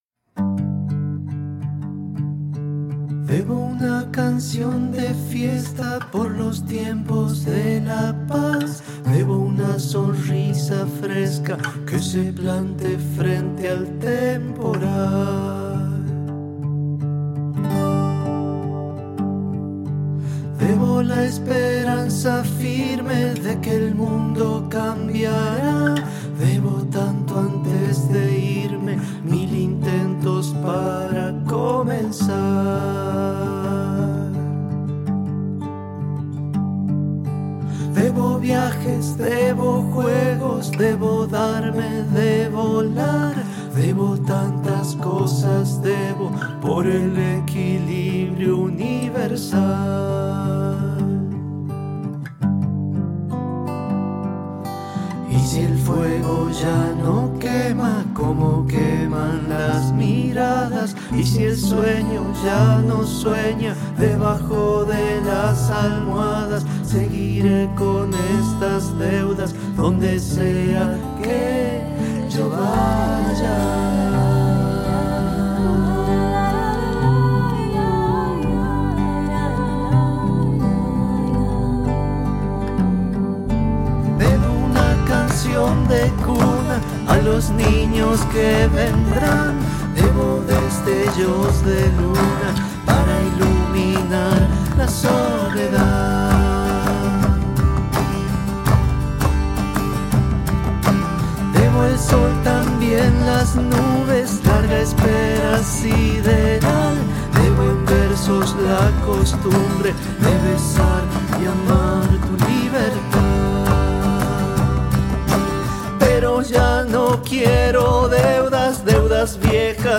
percusión
coros